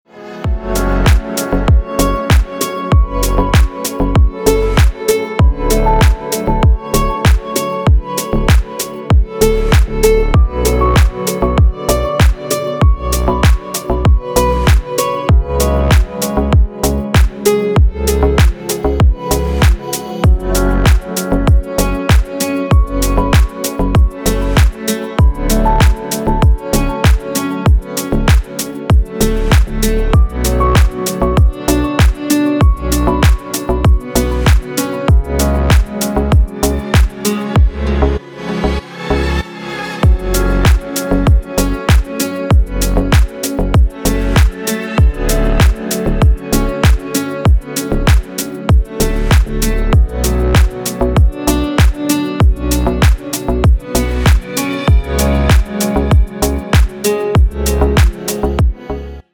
Душевная мелодия на звонок